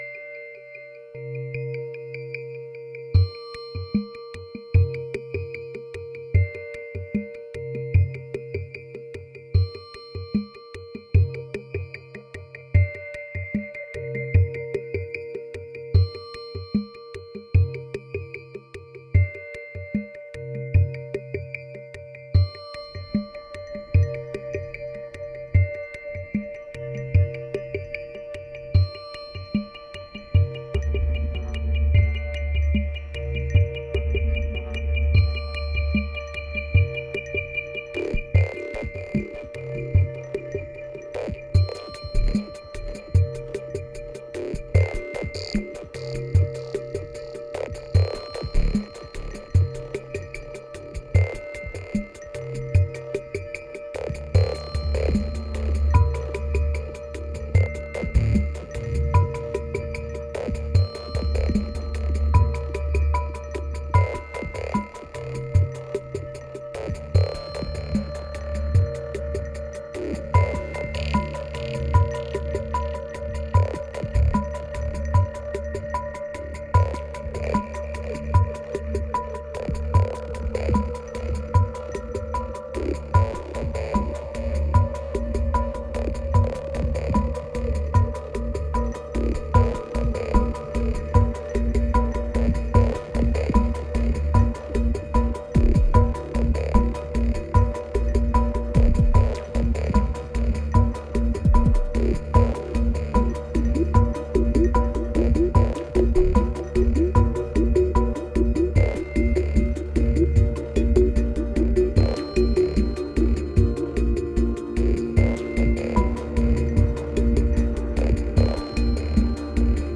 2187📈 - 63%🤔 - 75BPM🔊 - 2015-02-12📅 - 414🌟